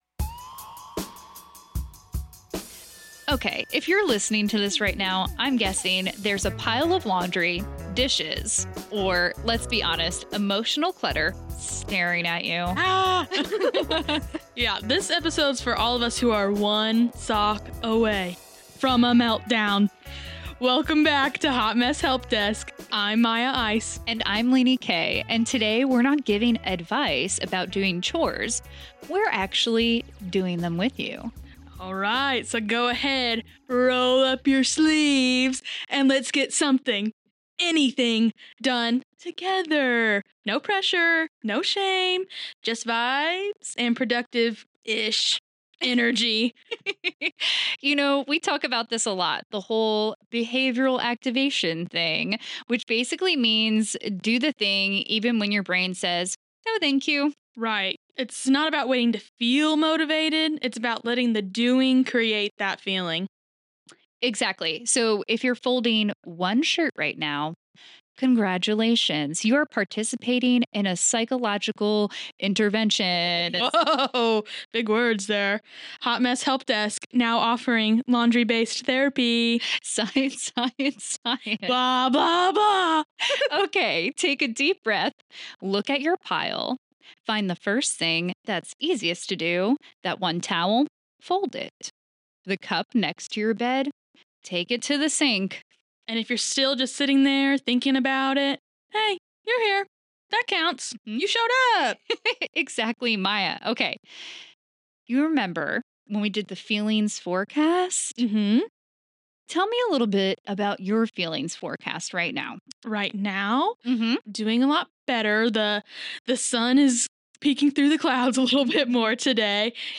We are two mental health counselors here to say-same.